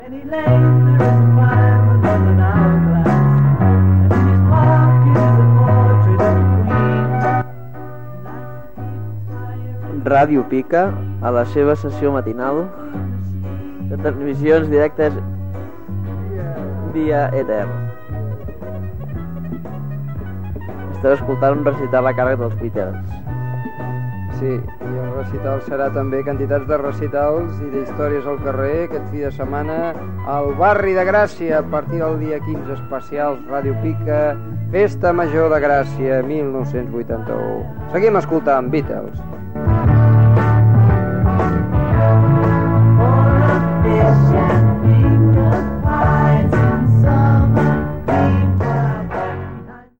Anunci emissió Festa Major de Gràcia 1981